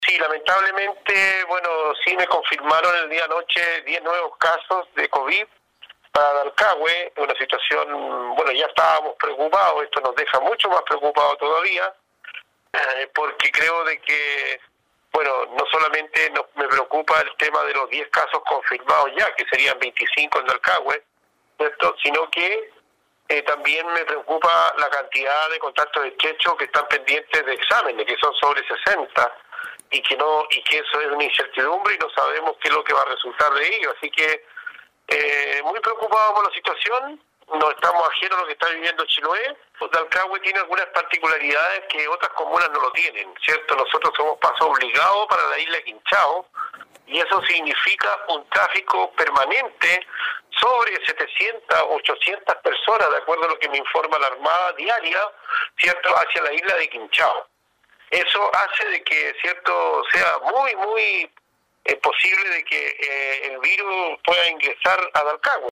Con ello ya suman 25 confirmados, según lo dio a conocer el alcalde de la comuna, Juan Hijerra, quien se mostró muy inquieto por el gran número de contactos estrechos que se originan con estos positivos, como lo aseguró en entrevista con radio Estrella del Mar.